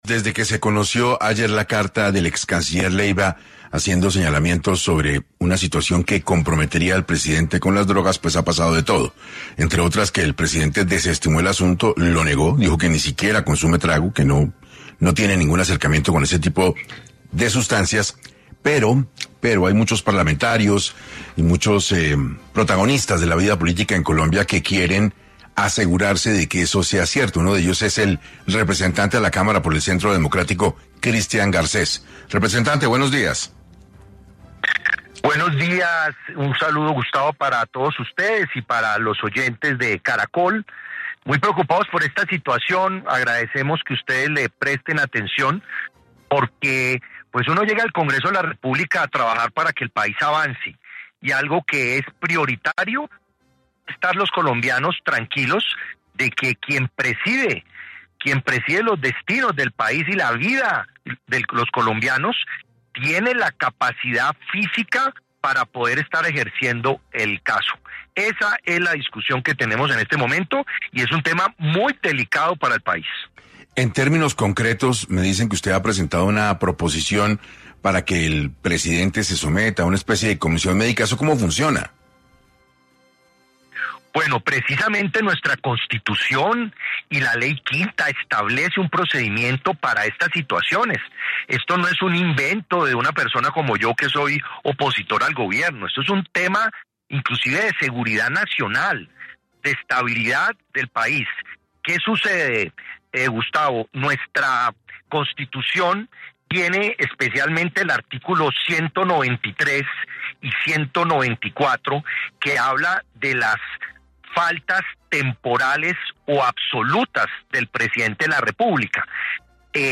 Christian Garcés, representante a la cámara, habló en 6AM sobre los posibles problemas de drogadicción que presenta el presidente Petro y cuál es el proceso a seguir.